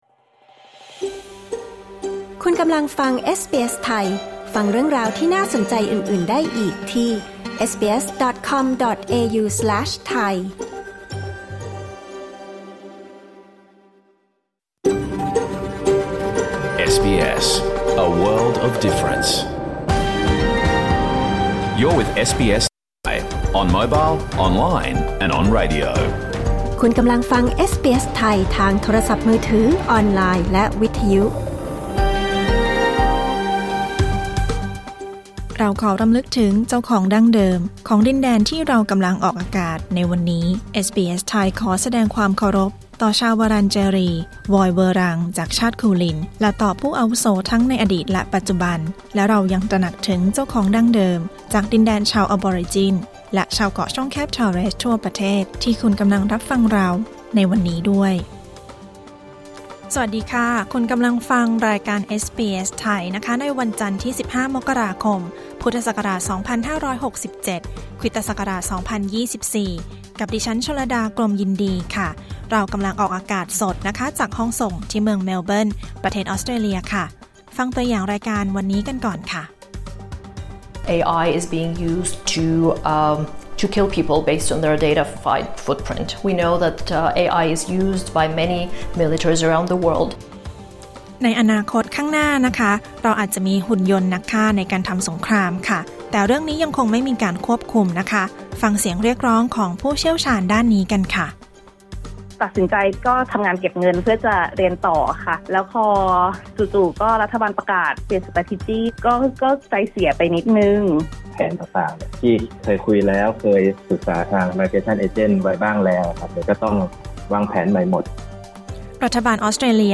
สรุปข่าวประจำวัน | สิ่งมีชีวิตใต้ทะเลที่อาจตัวเล็กแต่พิษร้าย | ข่าวไทย: วีซ่ามวยไทย นายกฯ เตรียมประชุม World Economic Forum และทางแก้หนี้นอกระบบ | บทสัมภาษณ์คนไทยที่อาจต้องชีวิตเปลี่ยนไป หากออสเตรเลียประกาศเปลี่ยนกฎตามยุทธศาสตร์ด้านการย้ายถิ่น (Migration Strategy) | เรียนภาษาอังกฤษศัพท์สำนวนในการตัดสินใจ